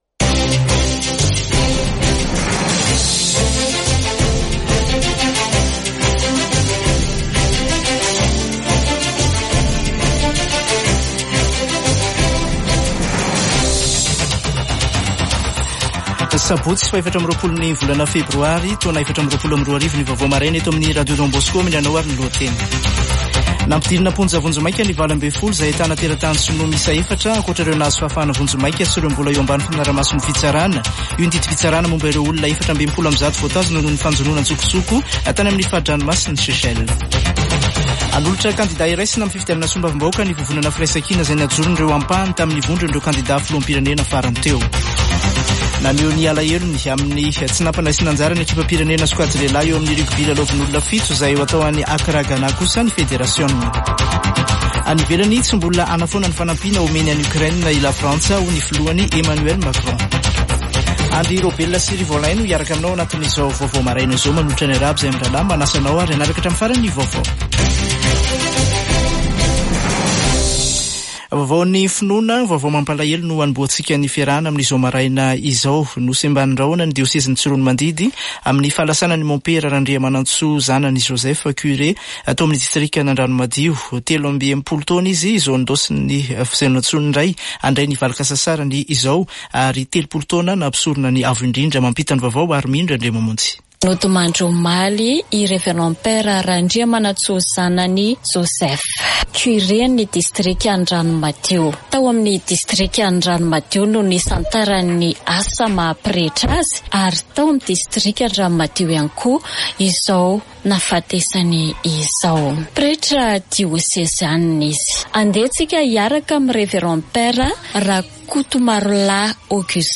[Vaovao maraina] Sabotsy 24 febroary 2024